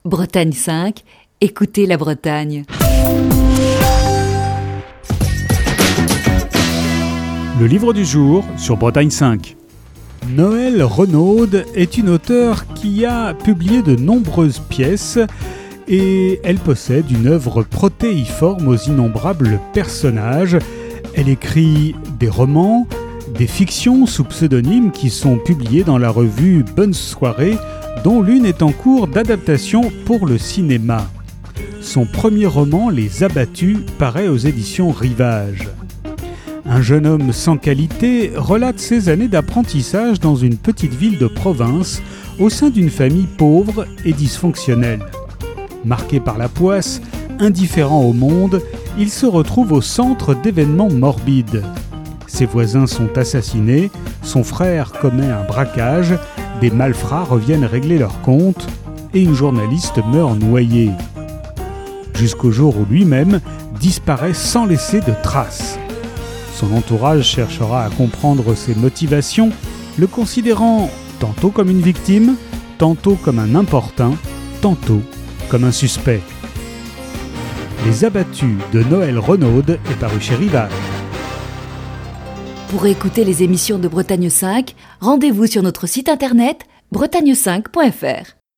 Chronique du 12 mars 2020.